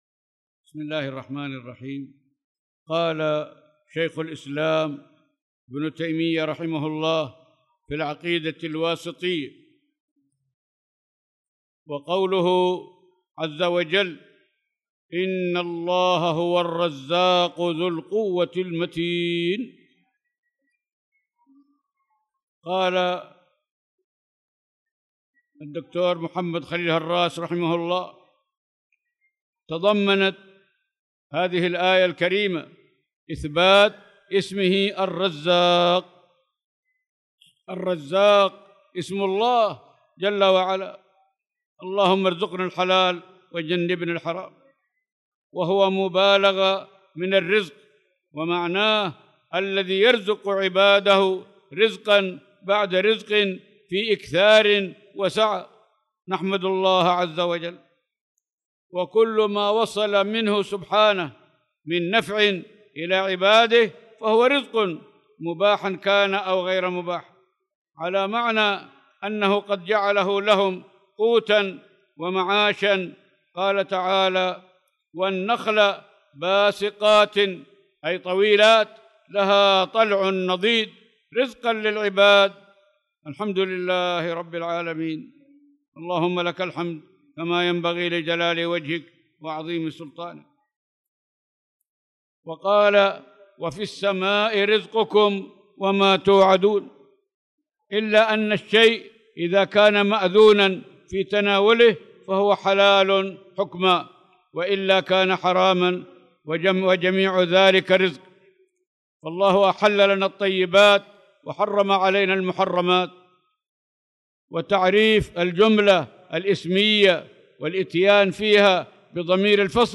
تاريخ النشر ١١ ذو القعدة ١٤٣٧ هـ المكان: المسجد الحرام الشيخ